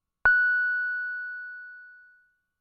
Roland Juno 6 Rich pluck " Roland Juno 6 Rich pluck F6 ( F5 Rich pluck 90127
标签： F6 MIDI音符-90 罗兰朱诺-6 合成器 单票据 多重采样
声道立体声